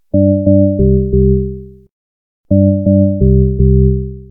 M1 organ bass is easy. It’s just four sine waves set to the right frequencies.
Here’s both versions, the FM7 version and my 2xWAVETONE version:
There’s no difference that I can hear.